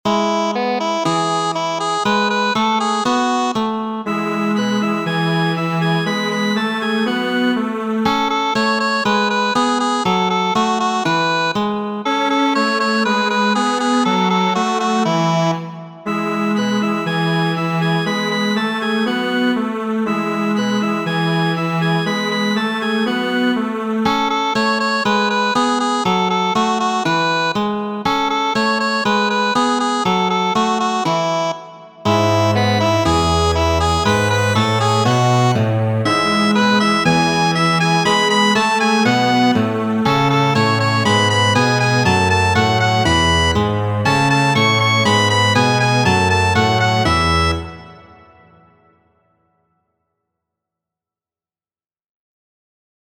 Boleslav, infankanto.
Muziko: Boleslav, Boleslav , ĉeĥa popolkanto.